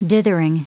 Prononciation du mot dithering en anglais (fichier audio)
Prononciation du mot : dithering